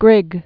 (grĭg)